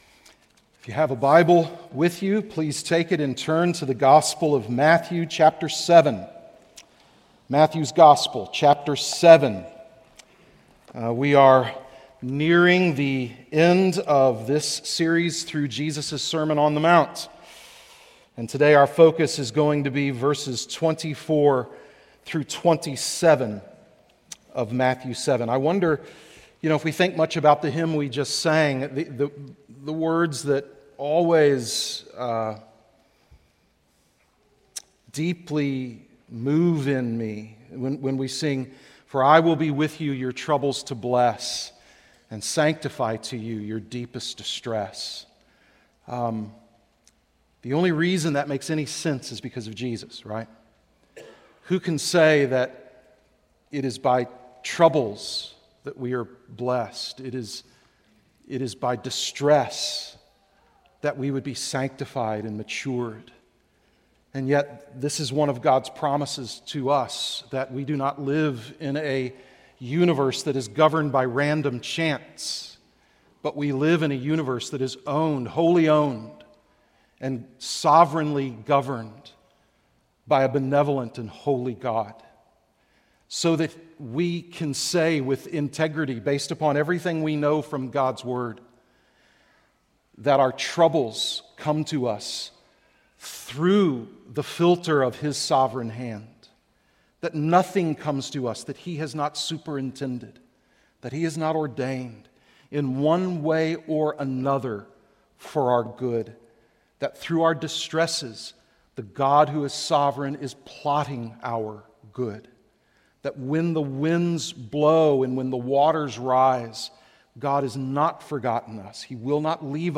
Series: The Sermon on the Mount